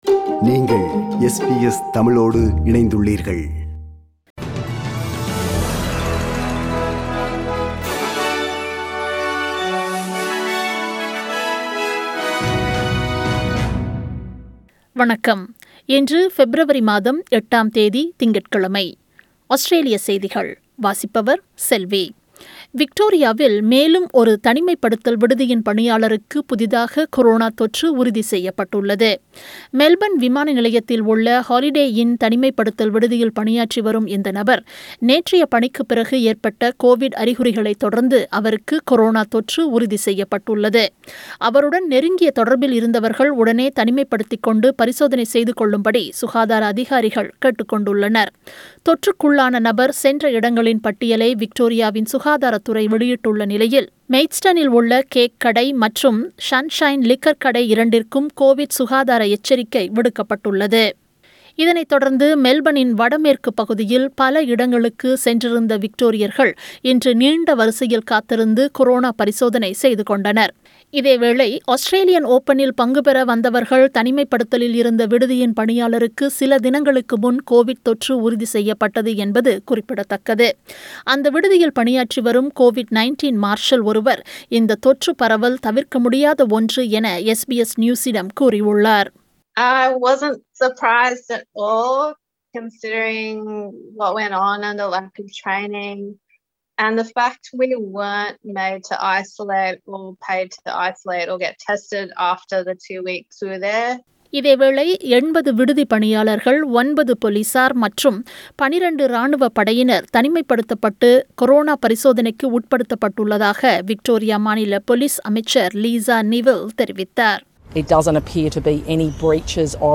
Australian news bulletin for Monday 08 February 2021.